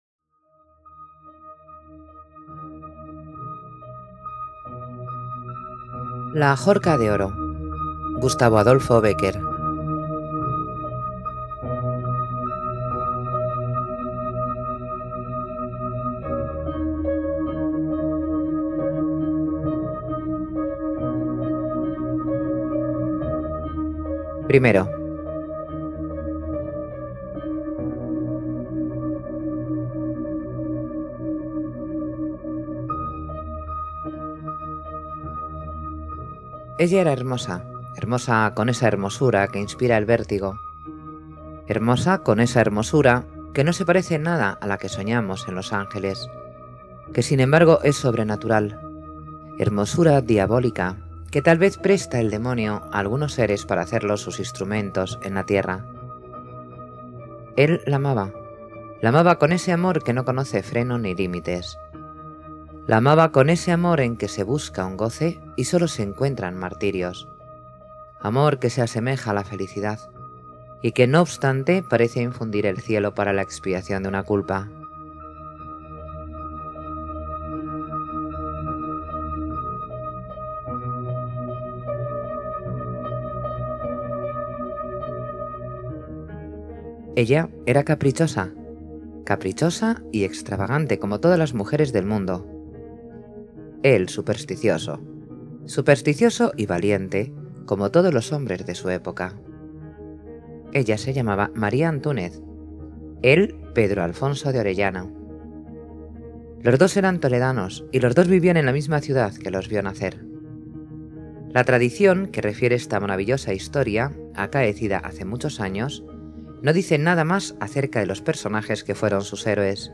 Leyenda
Música: Distimia y Antonio Raffone (cc:by-sa)